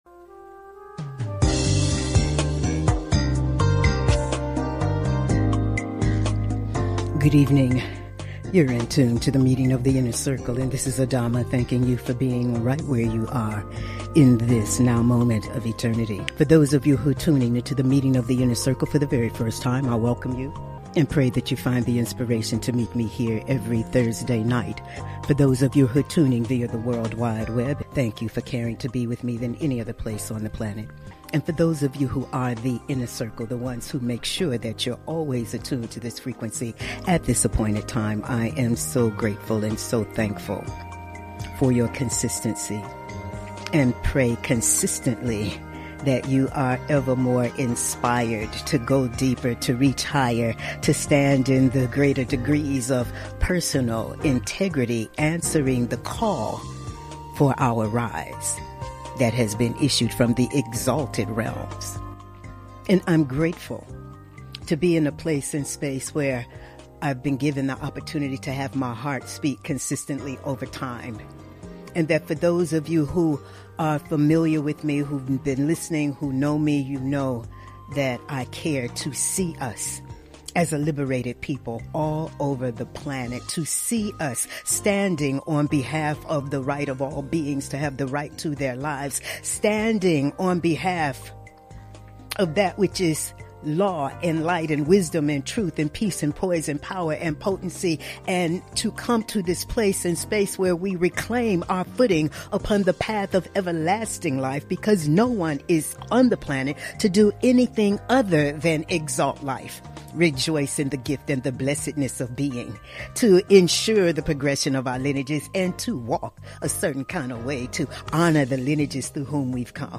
Monologues